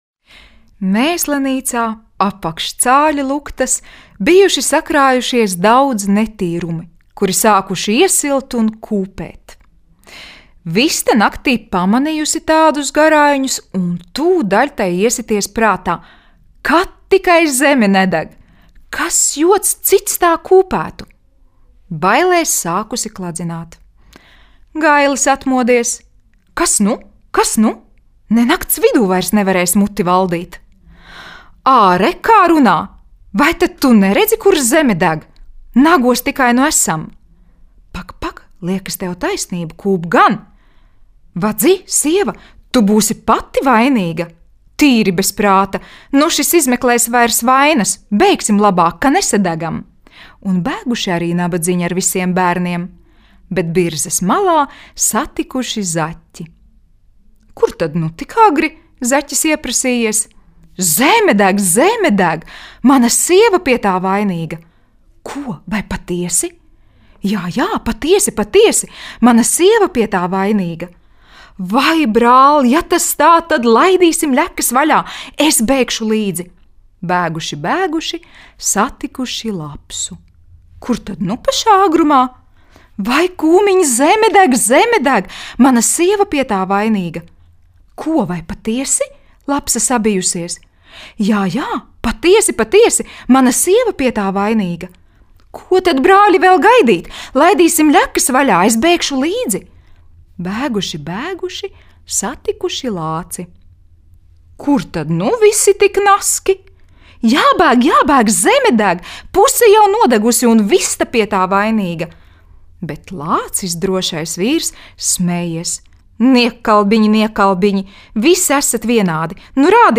Teicējs